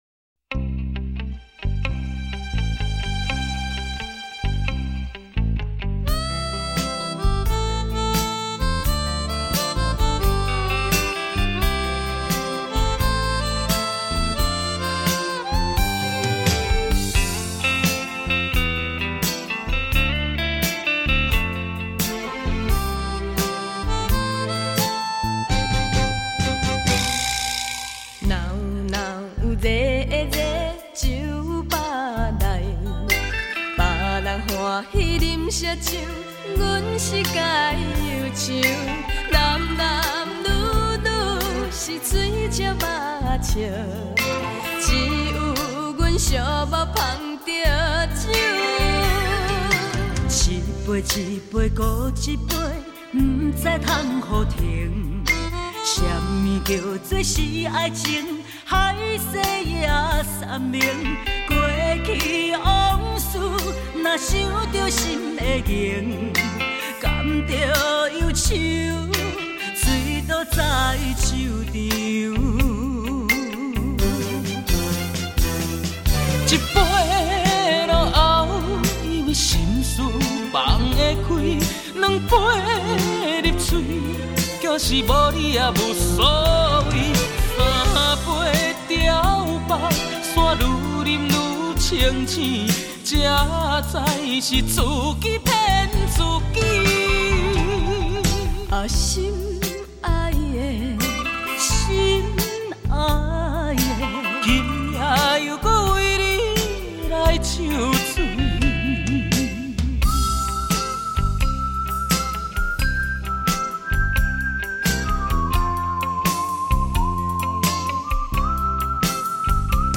怀旧的歌声